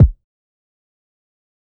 Karate Chop Kick.wav